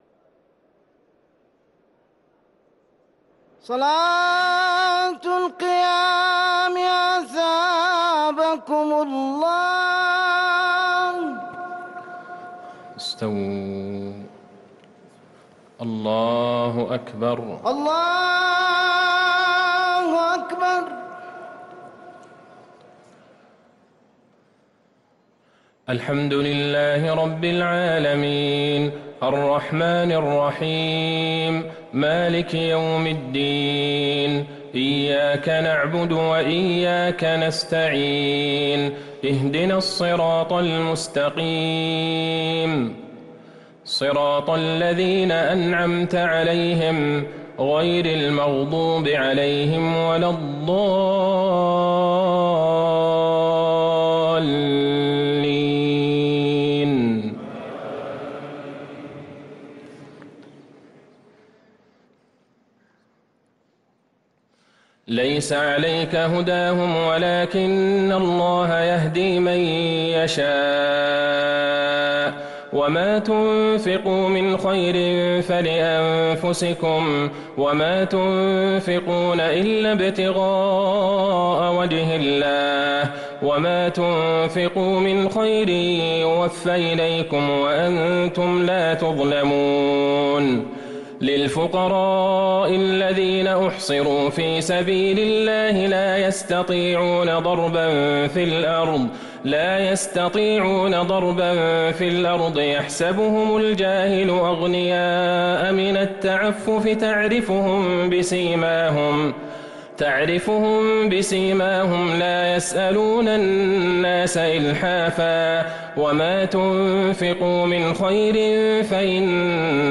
صلاة التراويح ليلة 4 رمضان 1444 للقارئ عبدالله البعيجان - الثلاث التسليمات الأولى صلاة التراويح